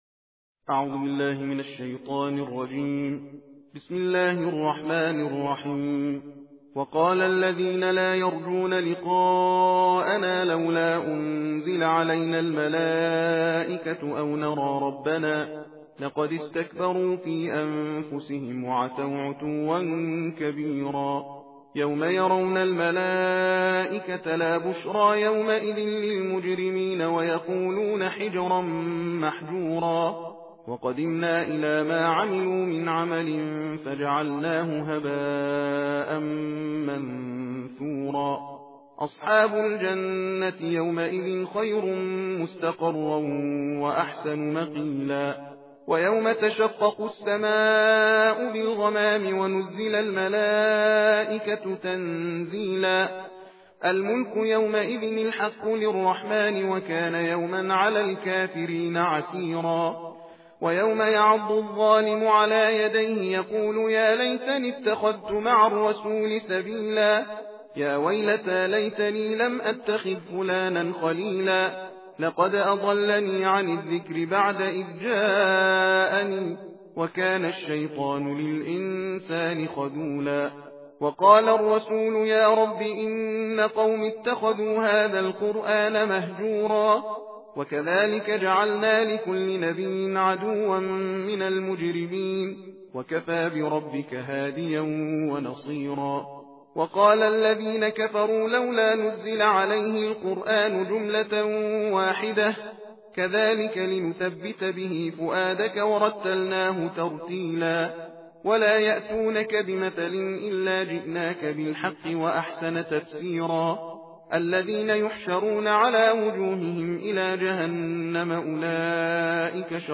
صوت/ تندخوانی جزء نوزدهم قرآن کریم